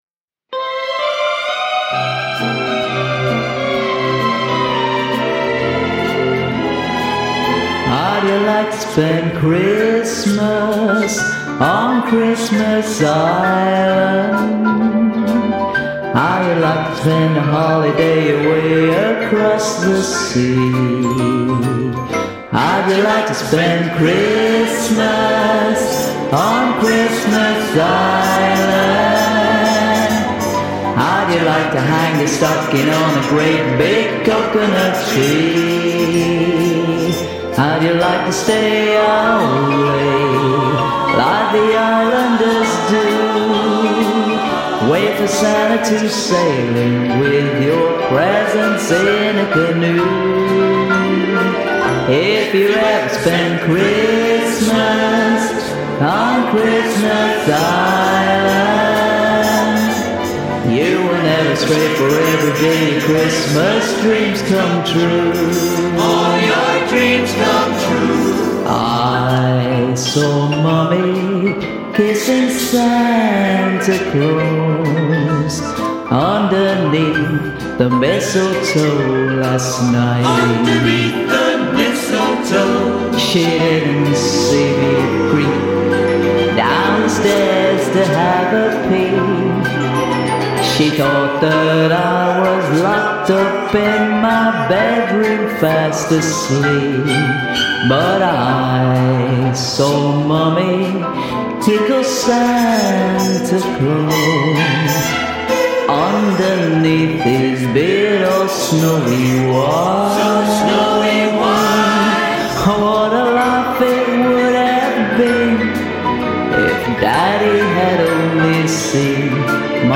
I engineered a medley album of well known Christmas songs